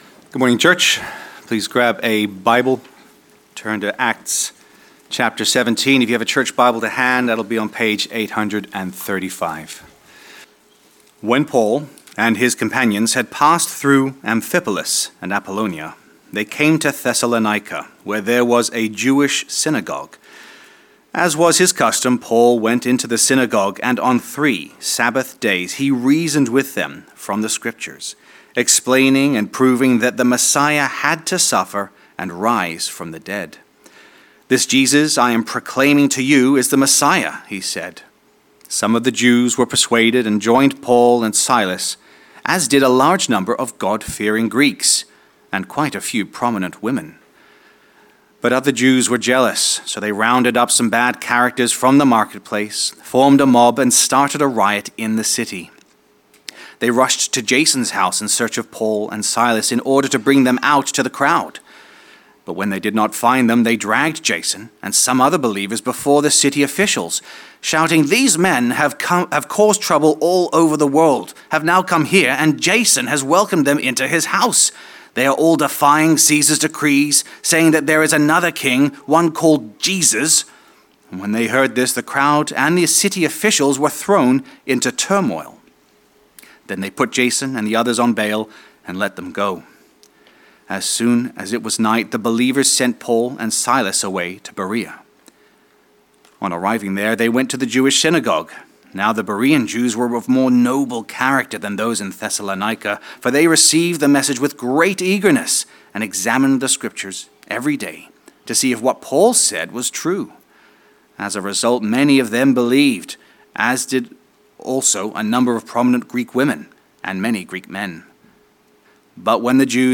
Weekly talks from Christ Church Balham's Sunday service